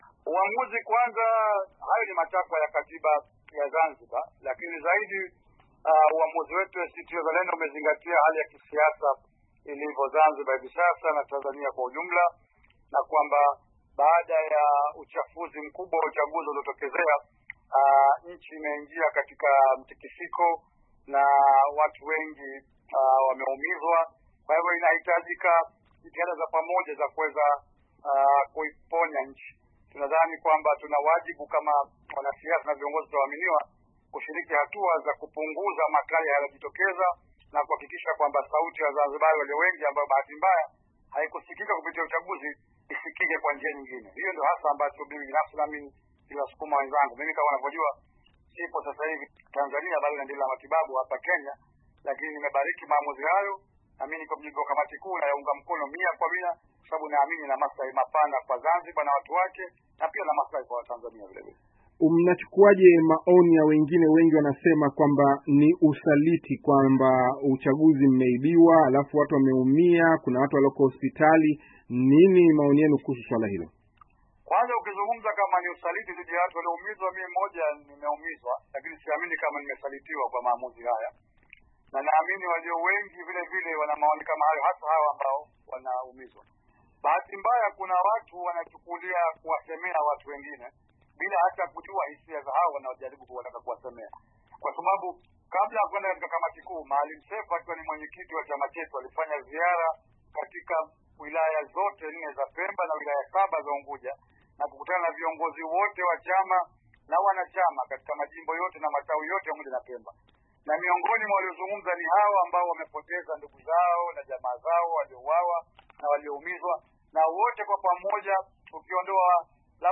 Akizungumza na Sauti ya Amerika akiwa hospitali mjini Nairobi siku ya Jumatatu